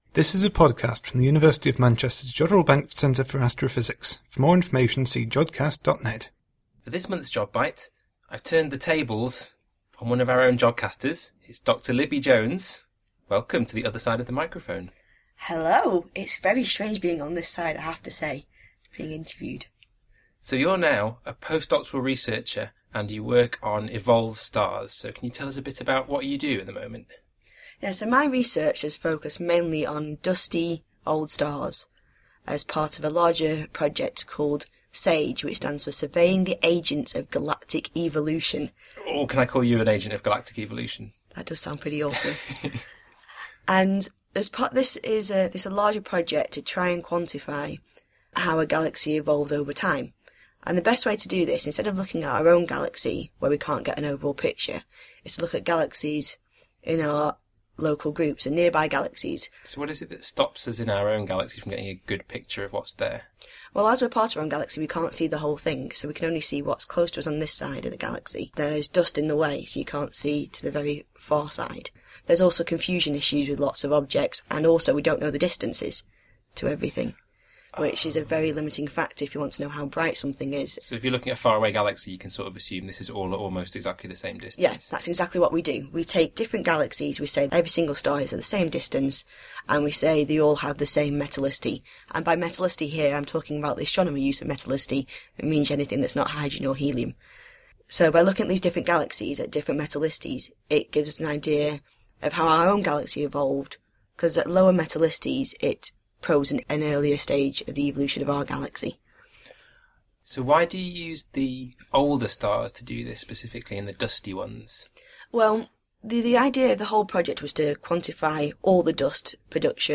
In this interview, she talks about her infra-red observations of the Magellanic Clouds - small neighbouring galaxies of the Milky Way that have low metallicity and resemble distant galaxies at earlier times in the history of the Universe. The older, or evolved, stars tell us about the life cycle of dust that is produced as a star ages and then destroyed by newborn stars.